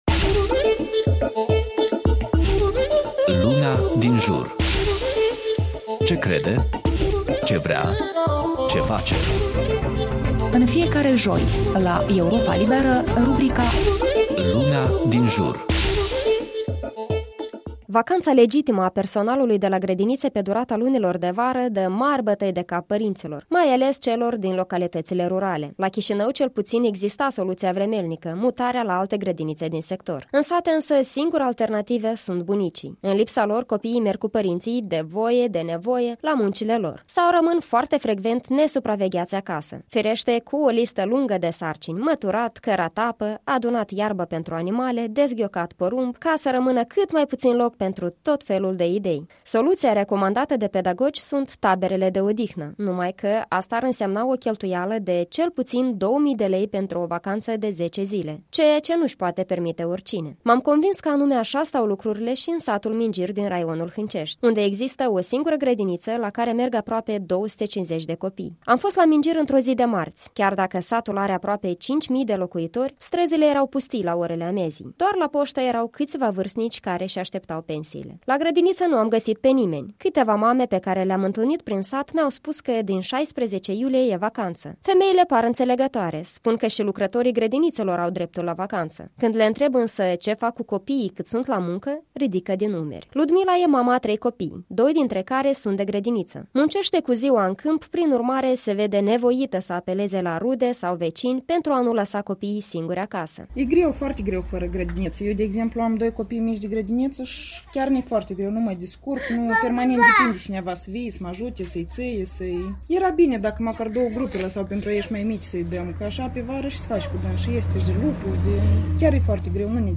Un reportaj de la Mingir, Hânceşti.